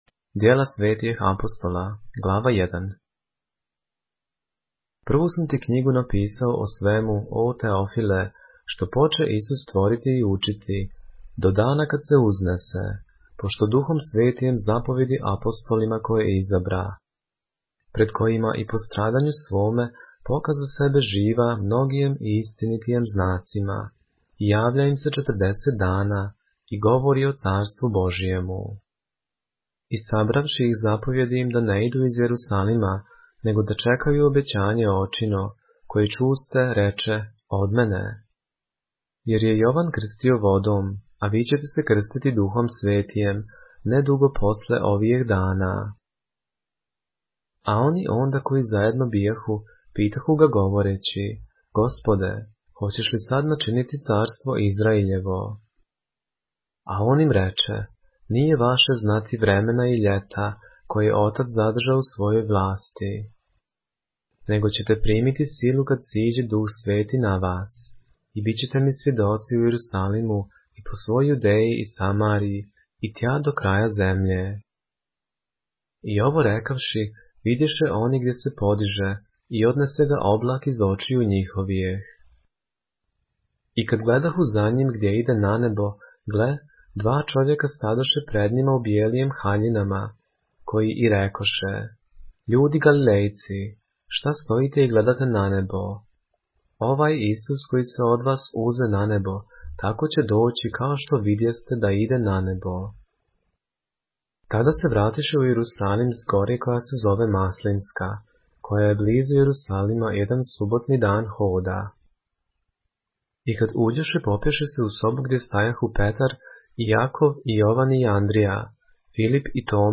поглавље српске Библије - са аудио нарације - ДЕЛА АПОСТОЛСКА 1